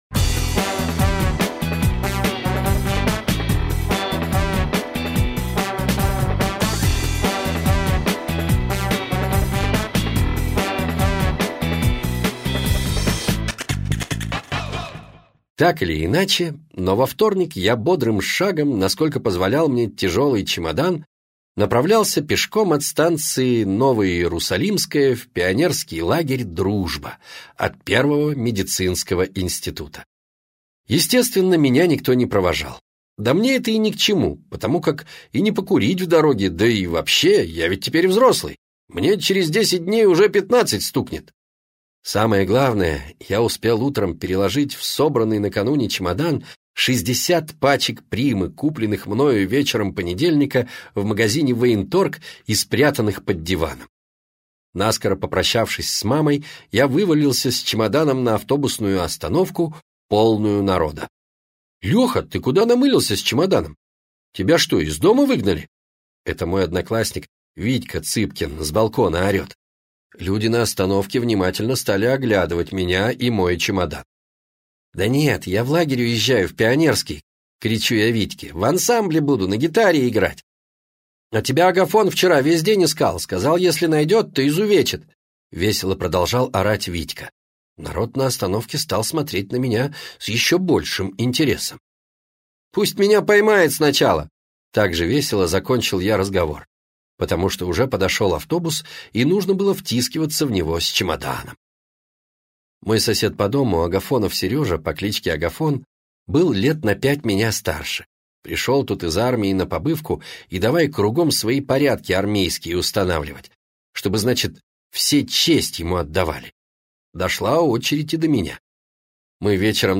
Аудиокнига Преступление доктора Паровозова | Библиотека аудиокниг
Прослушать и бесплатно скачать фрагмент аудиокниги